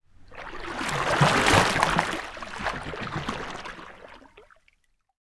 Water_50.wav